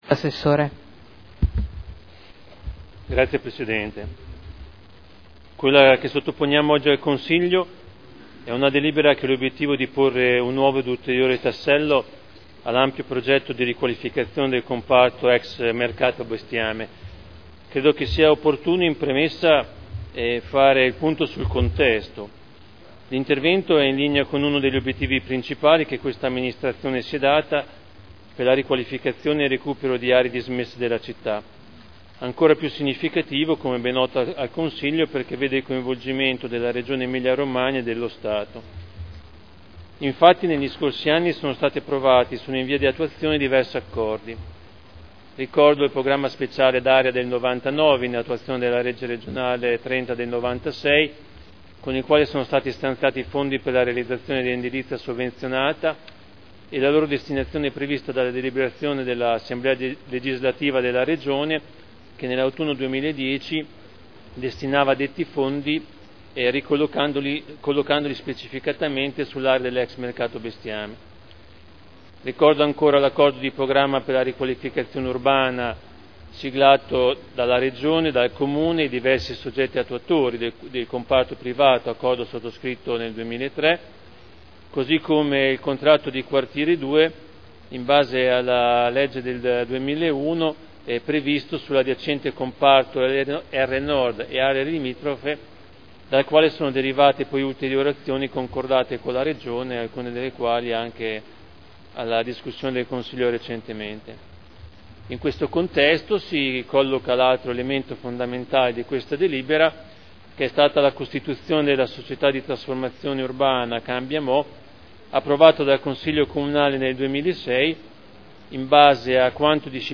Fabio Poggi — Sito Audio Consiglio Comunale
Seduta del 22/12/2011. Introduce proposta di deliberazione. Conferimento alla Società di Trasformazione Urbana CambiaMo S.p.A. di lotto edificabile presso il comparto Ex Mercato Bestiame – Approvazione